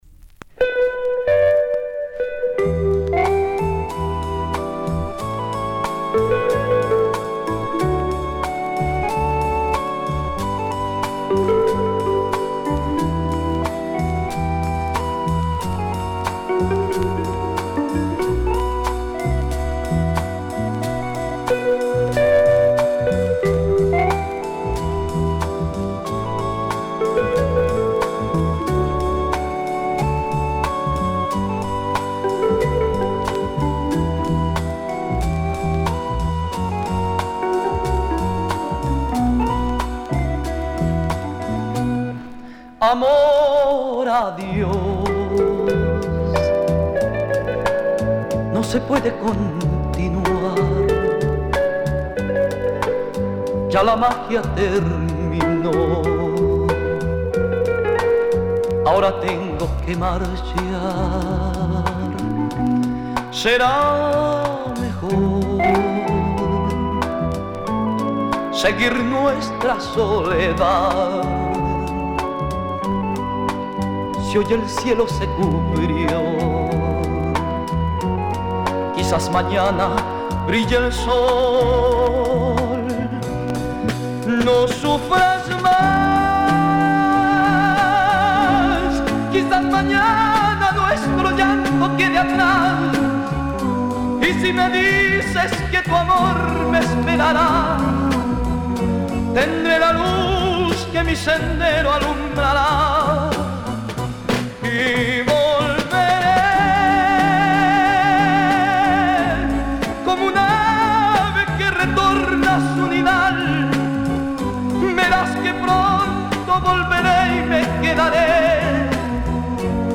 Published February 21, 2010 Boleros y Baladas Comments
I threw some great break-beat sounding boleros